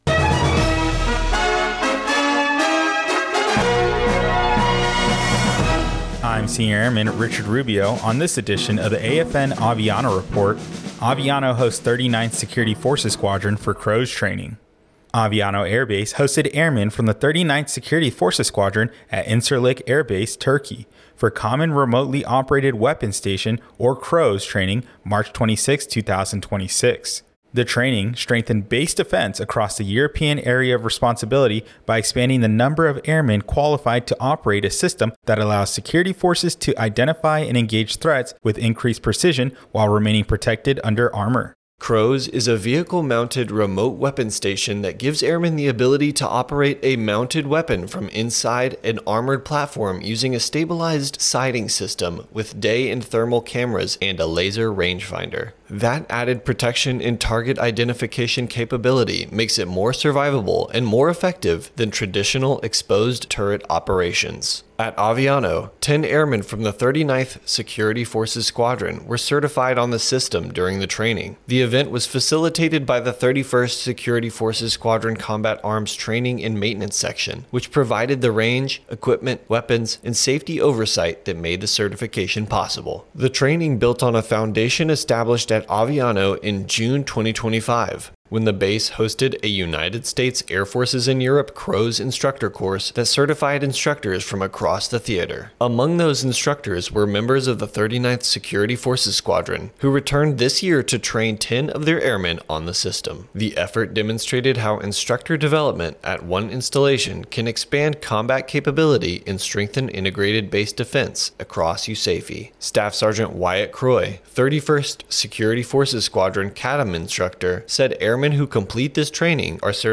AFN Aviano Radio News: Aviano Hosts 39th SFS for CROWS Training